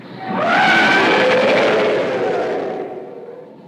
Yeti20thRoar.ogg